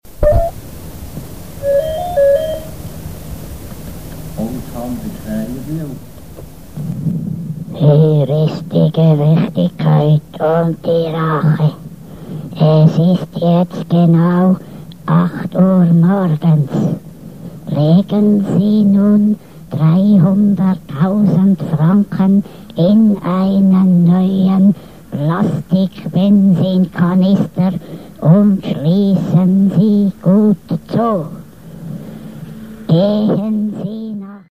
erpresseranruf.mp3